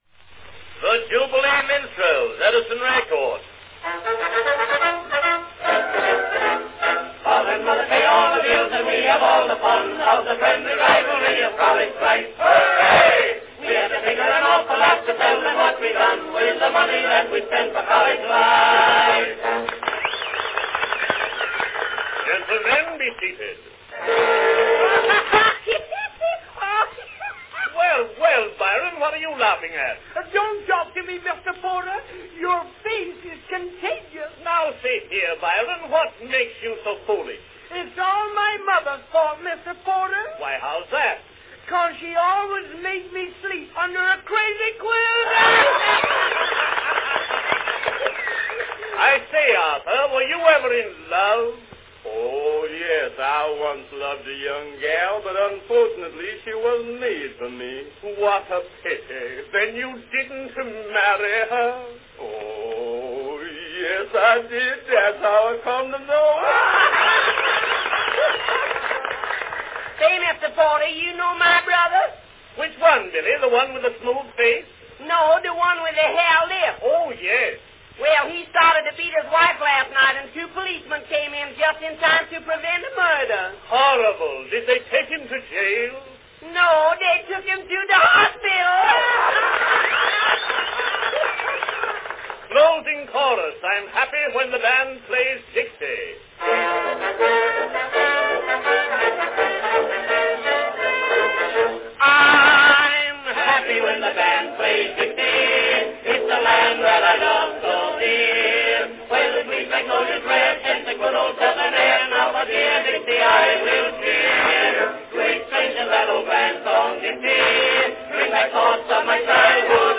A fun-filled minstrel recording from 1908: the Jubilee Minstrels.
Category Minstrels
Performed by Edison Minstrels
Announcement "The Jubilee Minstrels.   Edison record."
Enjoy this month's popular minstrel selection from Edison, squeezing into two minutes lots of laughs and featuring four of the biggest recording stars of the day: Arthur Collins, Byron G. Harlan, Billy Murray, and Steve Porter.
The curtain rises on "College Life," sung in real "rah! rah!" style by the entire company.   Then follows a volley of new and breezy jokes (no sign of a chestnut among them) by the Edison Company's famous coterie of minstrel men.
Arrangement original and not published.